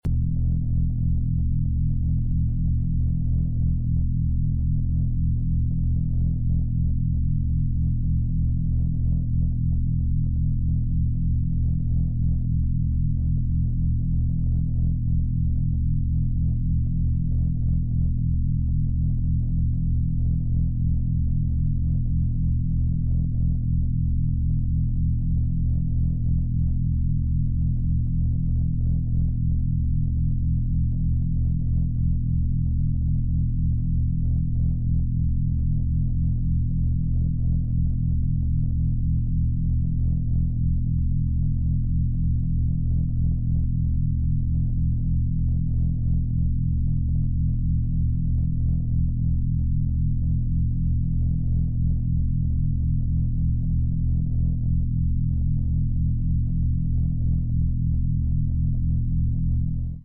40 Hz Gamma Binaural Beats sound effects free download
40 Hz Gamma Binaural Beats for focus and productivity.